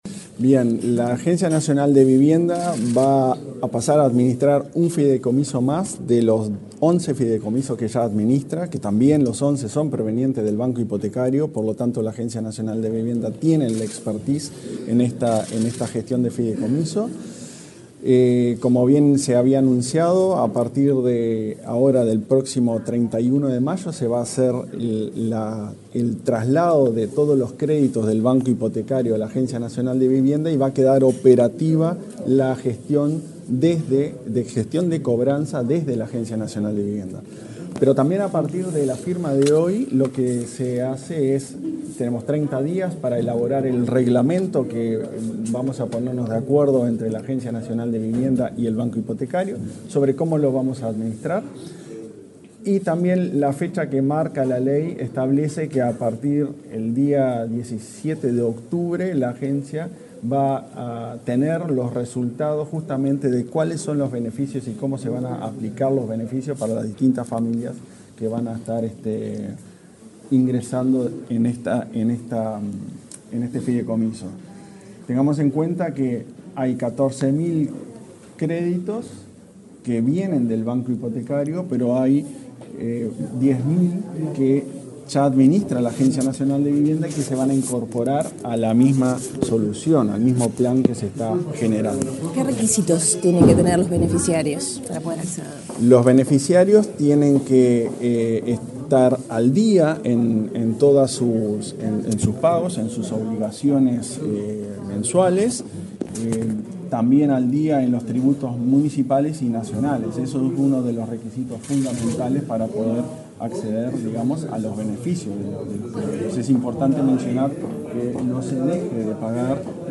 Declaraciones del presidente de la ANV, Klaus Mill
Luego dialogó con la prensa.